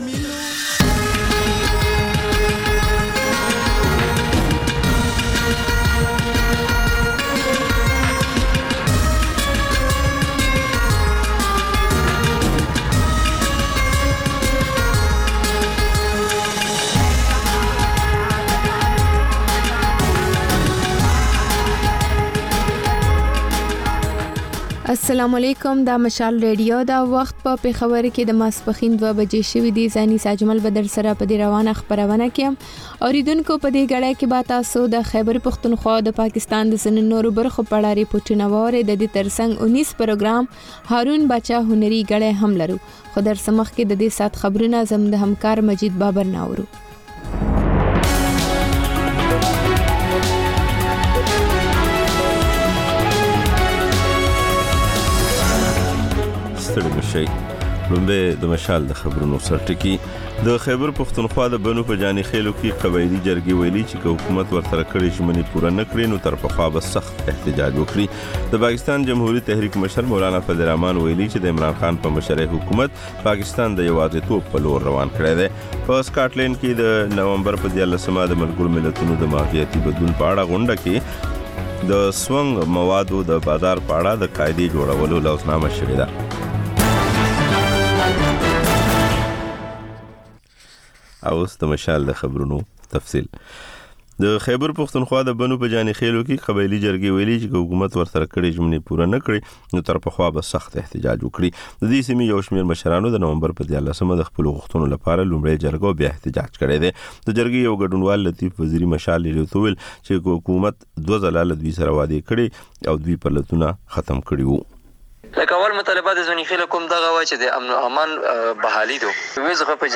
د مشال راډیو دویمه ماسپښینۍ خپرونه. په دې خپرونه کې لومړی خبرونه او بیا ځانګړې خپرونه خپرېږي.